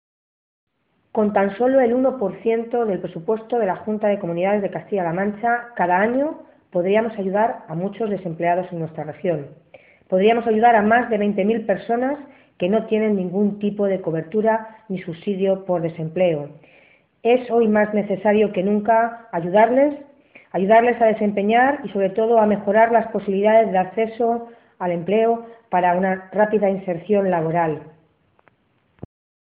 La portavoz de Empleo del PSOE en las Cortes regionales, Milagros Tolón, ha pedido hoy al Gobierno de Cospedal que “rectifique, escuche no solo a la oposición, sino a todos los agentes económicos y sociales y se ponga manos a la obra para abordar el principal problema que tenemos, que es el paro, a través de un gran pacto regional por el empleo”.
Cortes de audio de la rueda de prensa